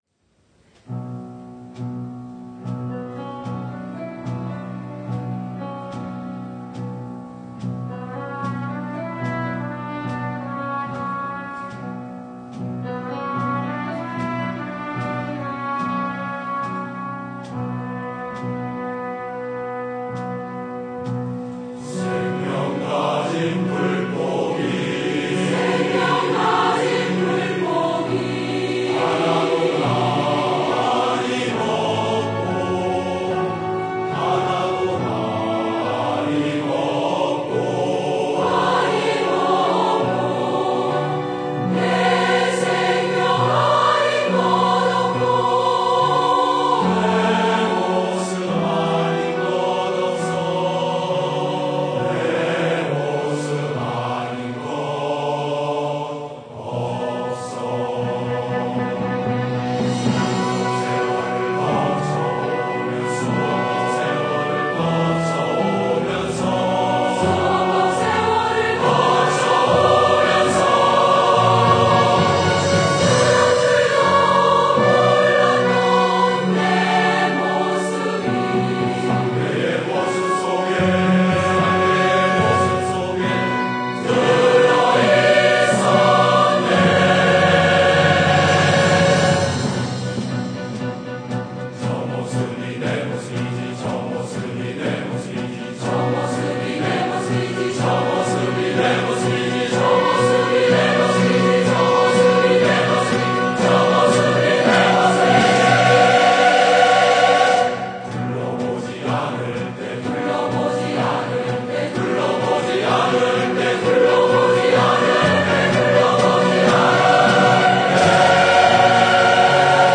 Here’s another spectacular Dharma song that gives me goosebumps every time I hear it. (Click on the link below to listen to it being sung by our choir at the Dharma song festival last autumn.)